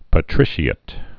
(pə-trĭshē-ĭt, -āt)